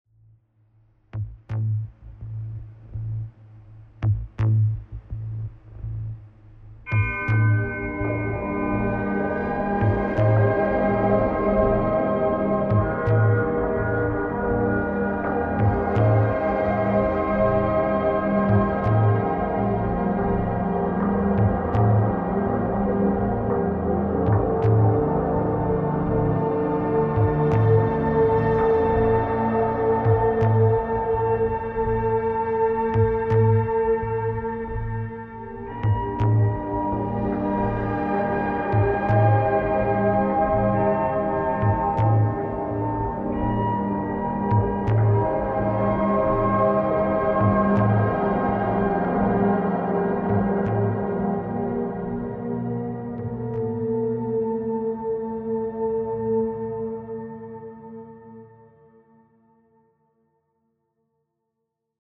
Horror. Nightmares and nerve-wracking tension